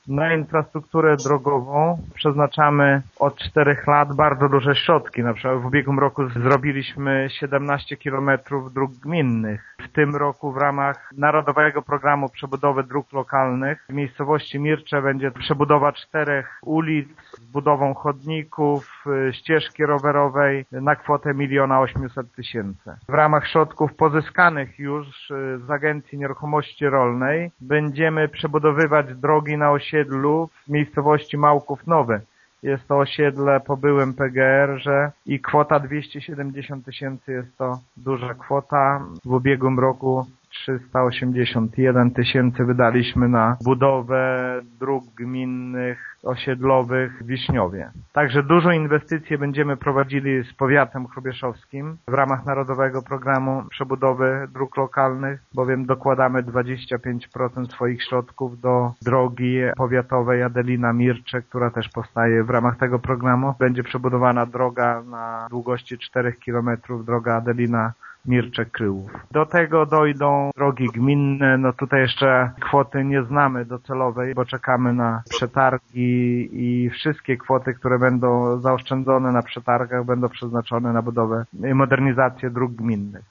Znaczne kwoty samorząd przeznaczy także na inwestycje drogowe. Większość z nich również będzie współfinansowana ze źródeł zewnętrznych – dodaje Lech Szopiński: